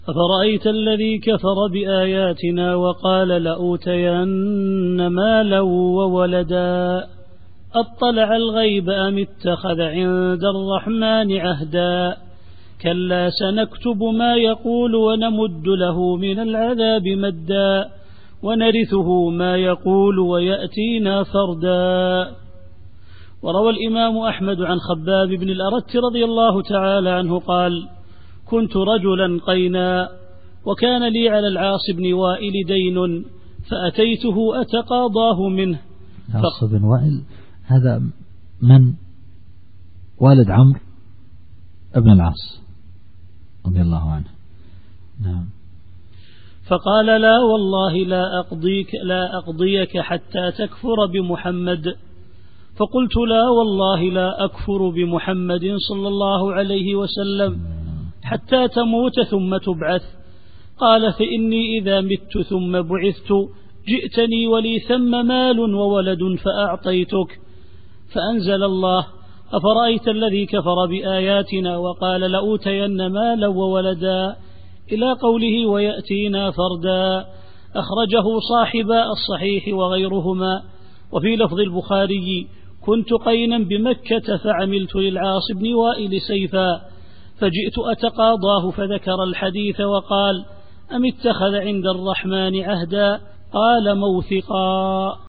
التفسير الصوتي [مريم / 77]